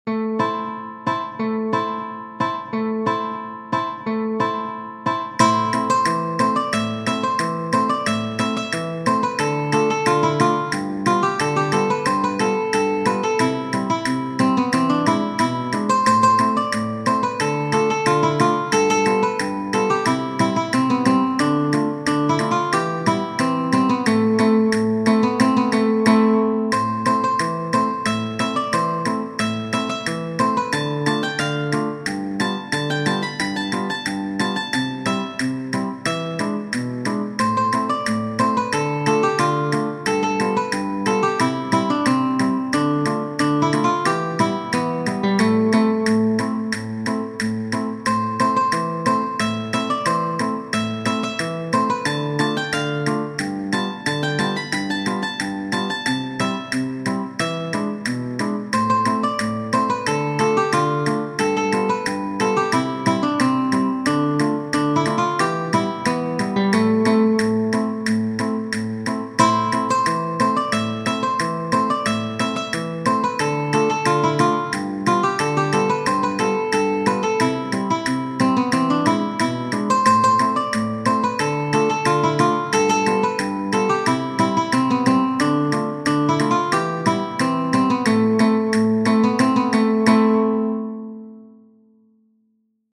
Tradizionale Genere: Folk "Keren chave", noto anche come "Keren, savoràle, drom" e "Amari szi, amari" è una canzone gitana originaria dell'Ungheria.